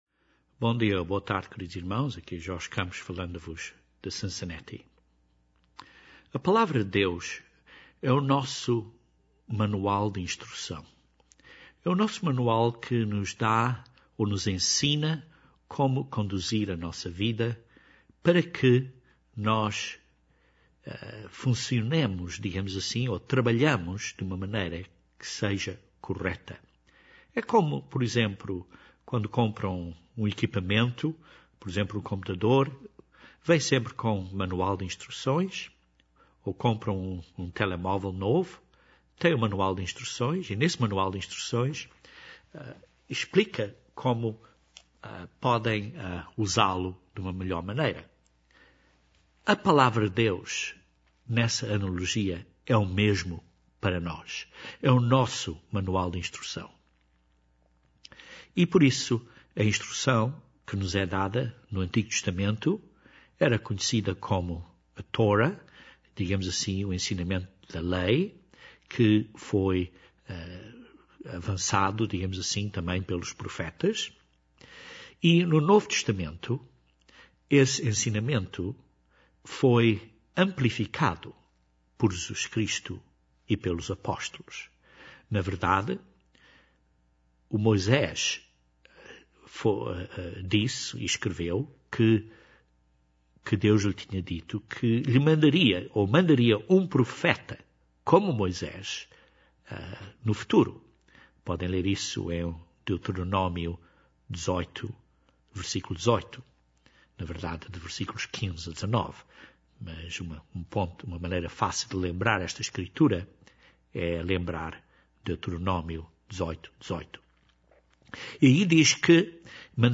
Este sermão é parte duma série de sermões acerca dos ensinamentos de Jesus Cristo, e particularmente acerca destas 'bem-aventuranças', ou belas atitudes Cristãs. A atitude de lamentar, chorar e ser sóbrio é uma que produz resultados importantes no crescimento da vida Cristã.